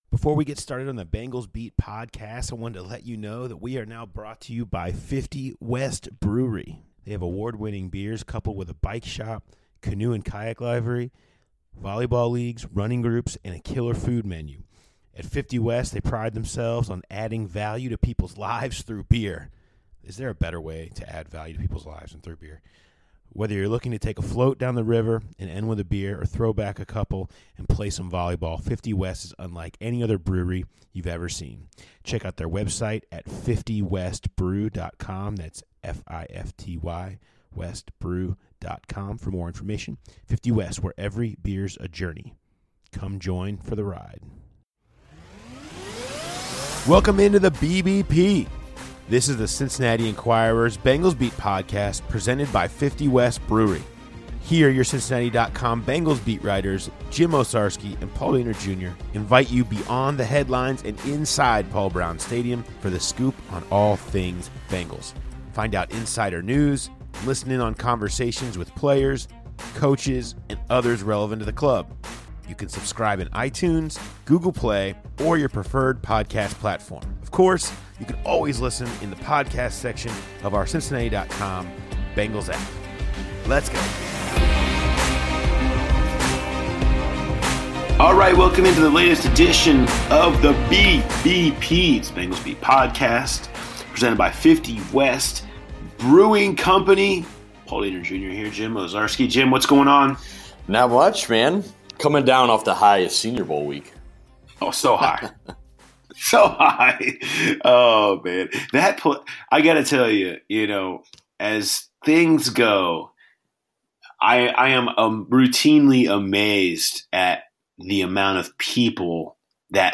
Hear directly from both Brown and Marvin Lewis.